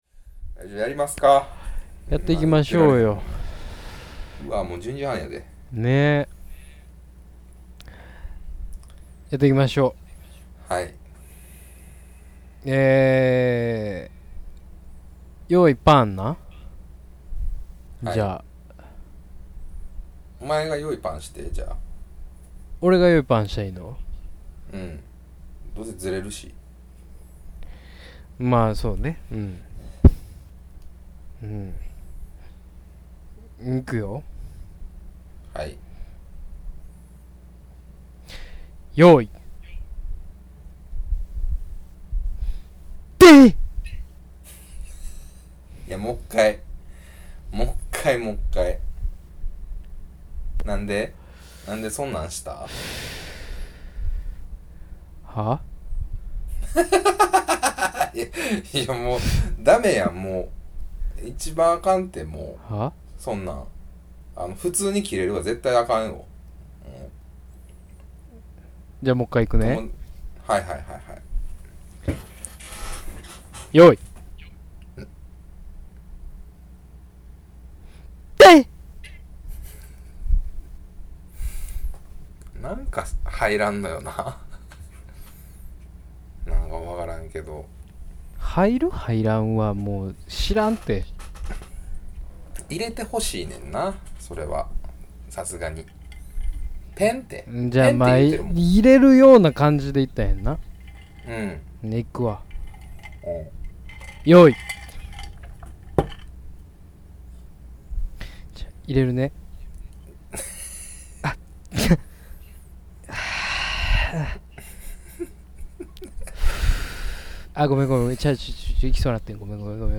今回からリモート収録です。